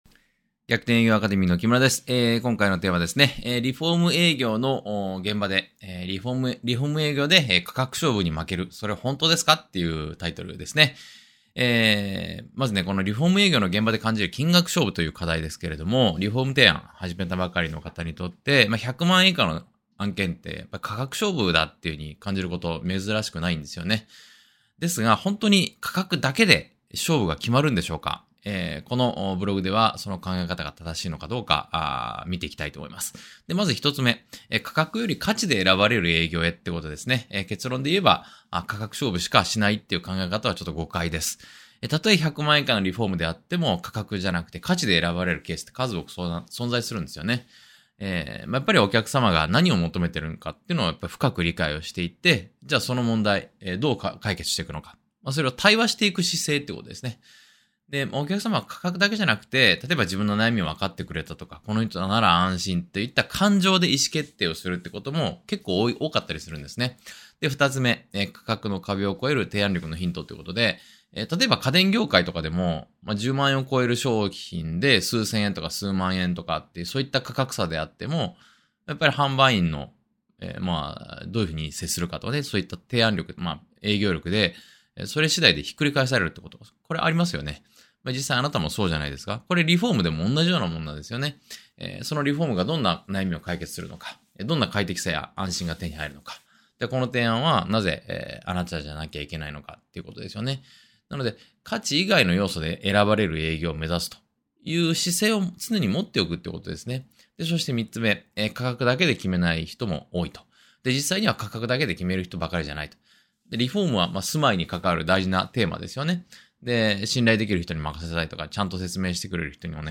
音声回答（︙をクリック→ダウンロード）